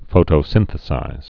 (fōtō-sĭnthĭ-sīz)